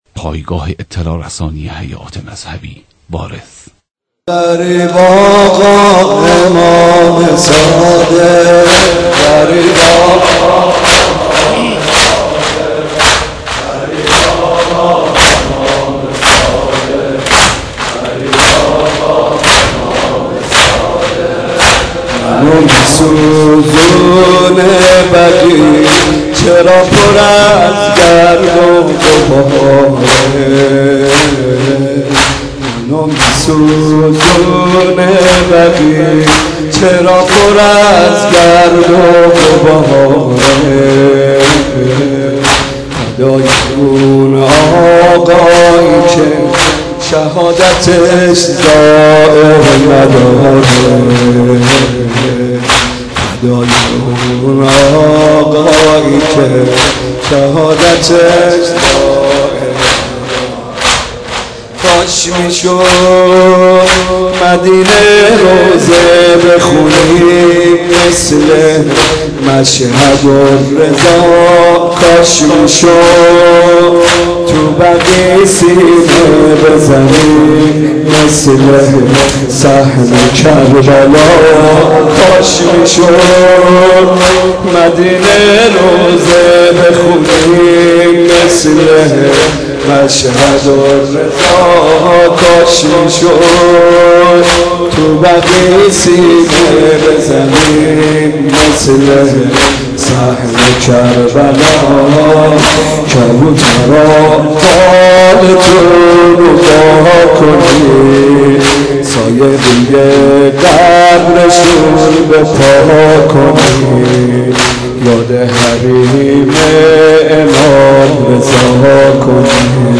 مداحی حاج محمد رضا طاهری به مناسبت شهادت امام صادق (ع)